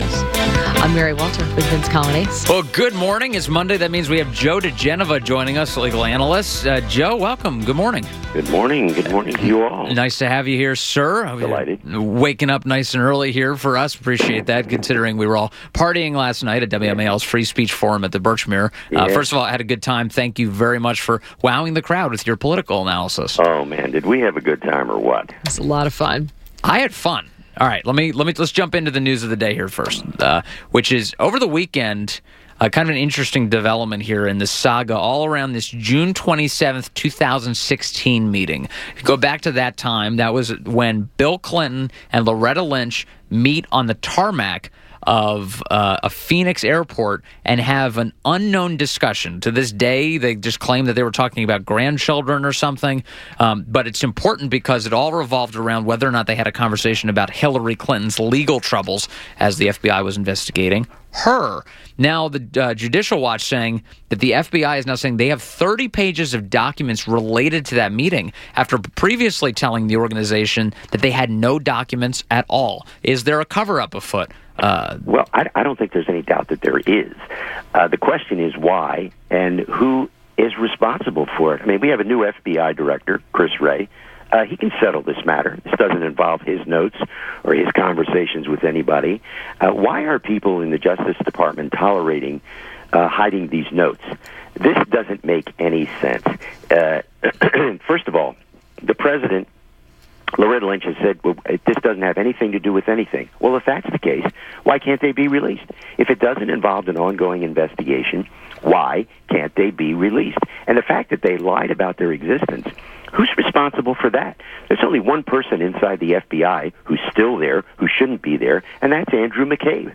7:05 - INTERVIEW - JOE DIGENOVA - legal analyst and former U.S. Attorney to the District of Columbia